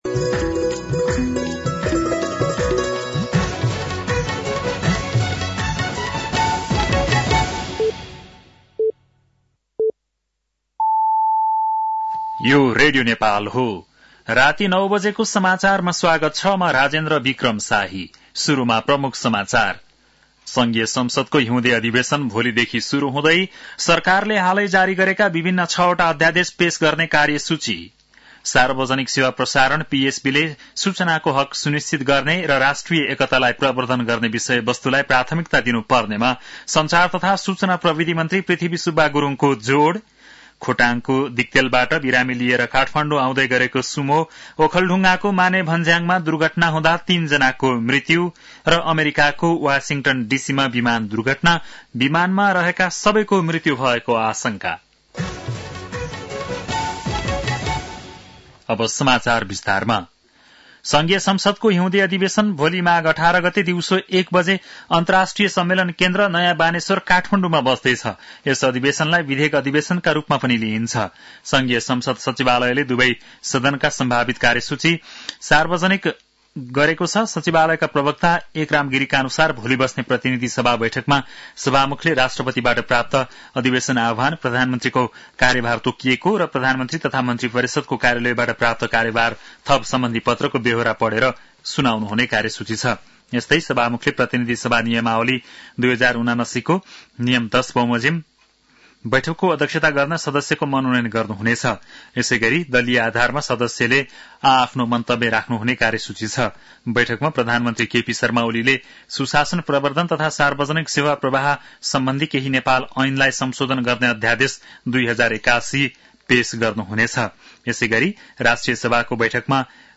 बेलुकी ९ बजेको नेपाली समाचार : १८ माघ , २०८१
9-PM-Nepali-NEWS-10-17.mp3